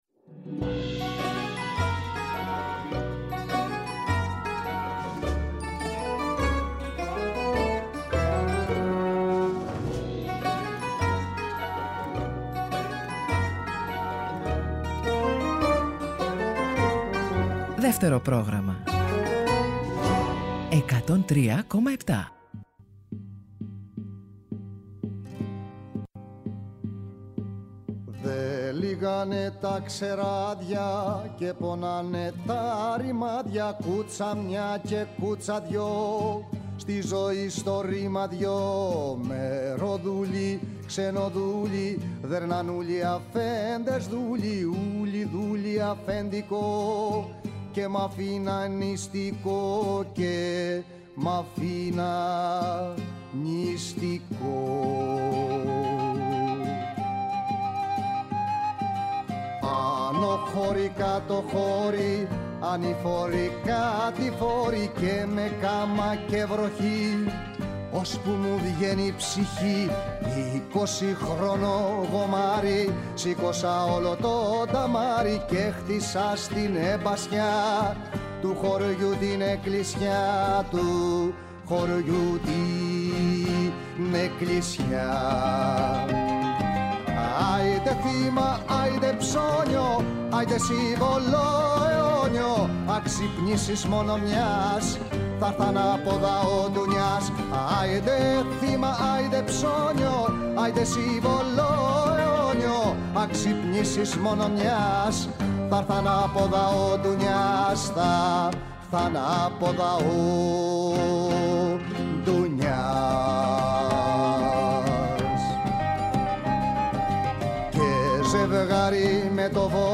“Ροκ συναναστροφές” με το …”ροκ” όχι μόνο ως μουσική φόρμα, αλλά περισσότερο ως στάση ζωής. Αγαπημένοι δημιουργοί και ερμηνευτές αλλά και νέες προτάσεις, αφιερώματα και συνεντεύξεις, ο κινηματογράφος, οι μουσικές και τα τραγούδια του.